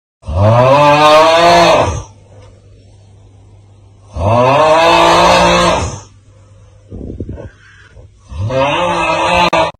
AUUGHHH-3.mp3